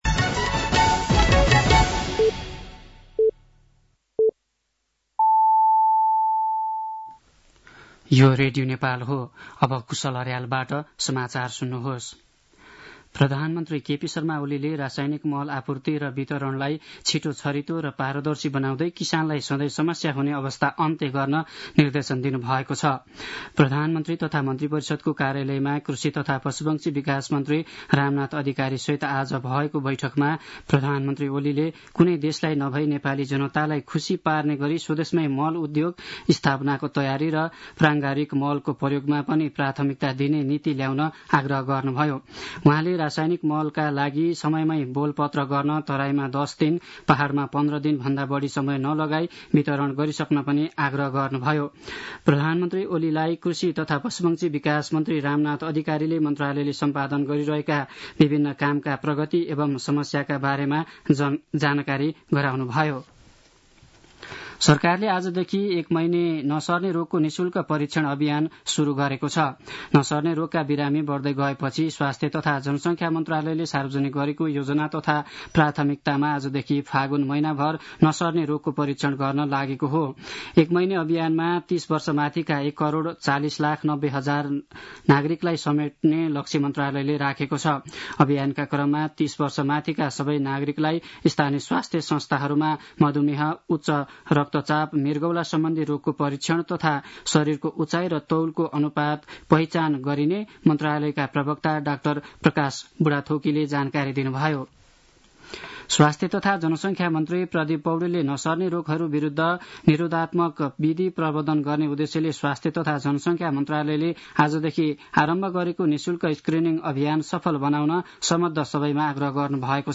साँझ ५ बजेको नेपाली समाचार : २ फागुन , २०८१
5-pm-nepali-news-11-01.mp3